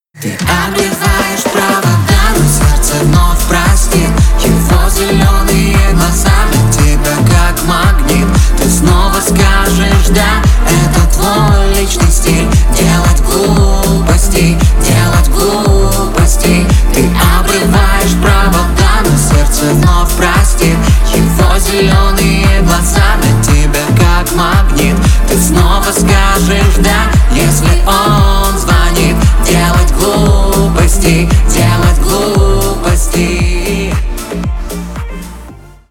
клубные